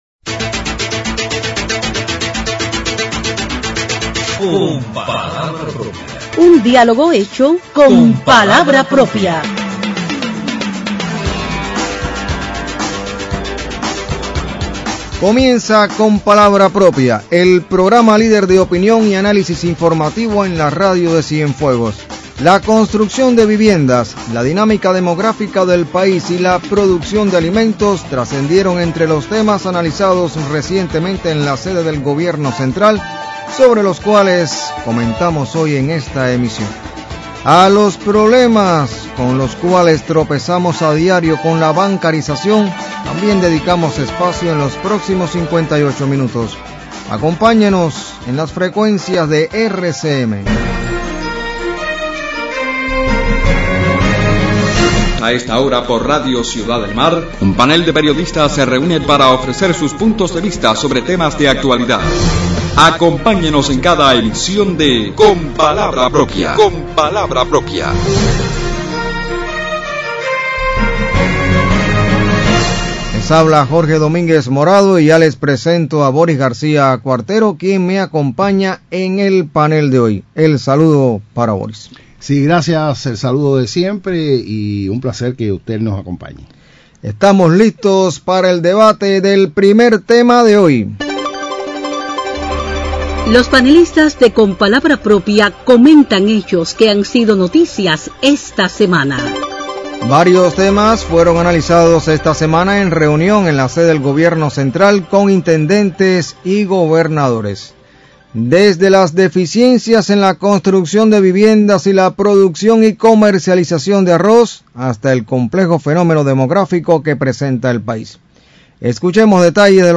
Los problemas de la bancarización también motiva los puntos de vista de los panelistas.